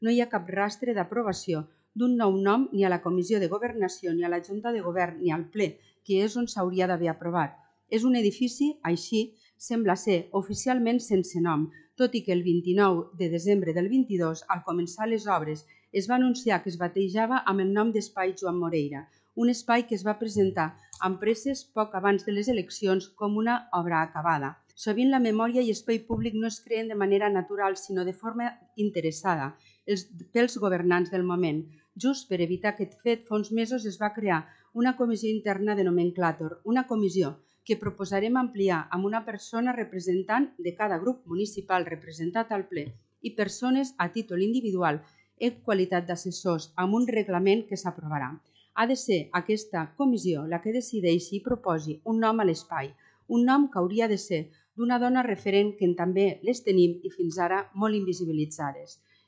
La portaveu d’ERC i regidora de Memòria democràtica,  Mar Lleixà ha denunciat que l’anterior governde Junts,  no va obrir cap expedient  per batejar de forma oficial la seu d’entitats culturals a l’antiga fàbrica Samo, amb el nom de Moreira.